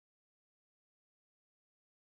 MOOG 1.wav